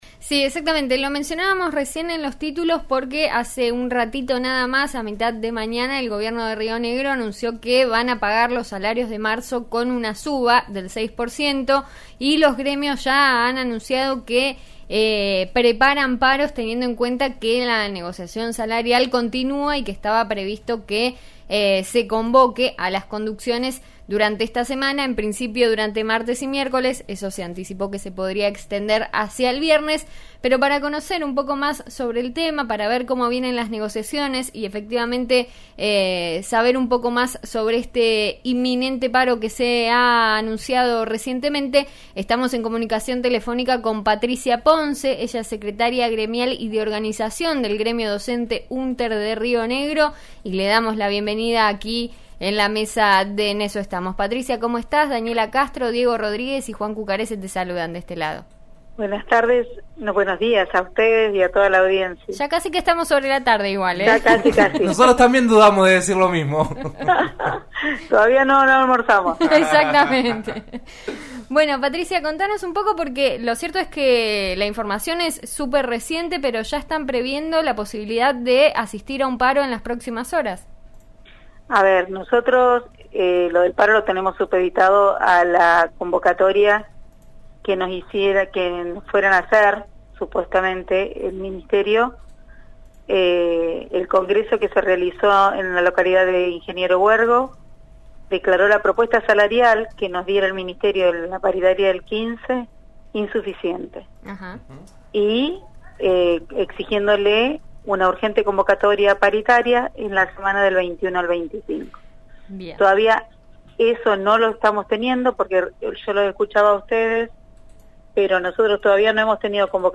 En eso estamos de RN Radio (89.3) dialogó este lunes